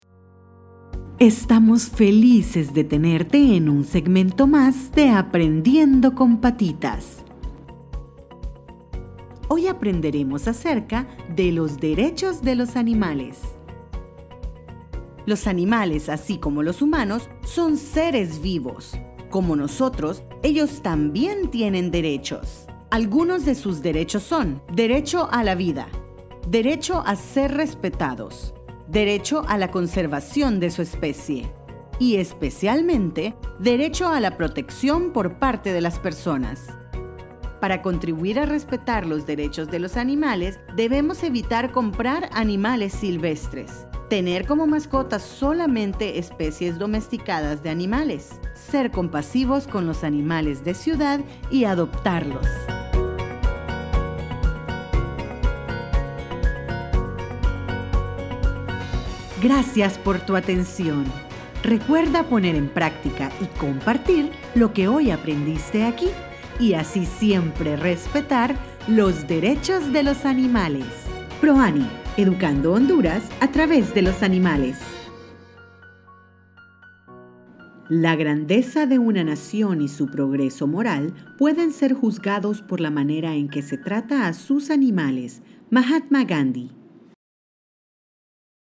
Native Spanish speaker, english voiceover, cheerful, bright, serious, convincing, conversational, corporate, educational, commercial spots.
Sprechprobe: eLearning (Muttersprache):